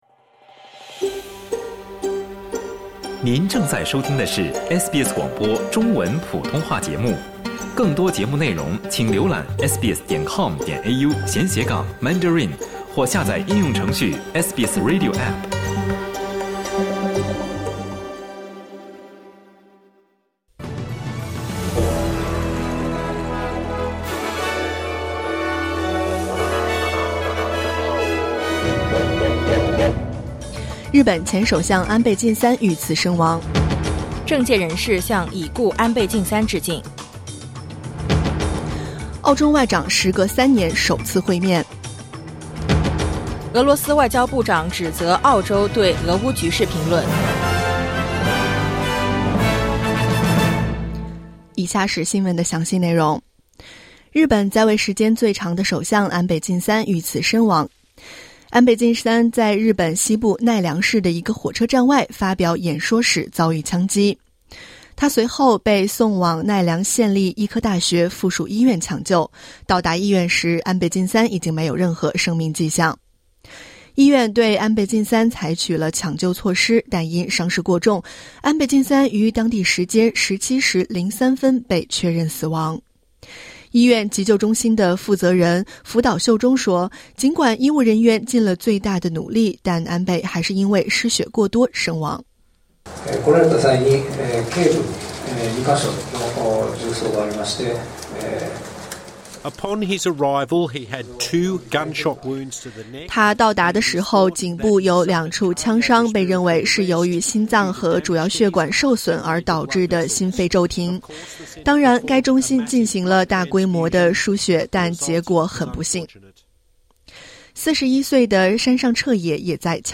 SBS早新闻(2022年7月9日）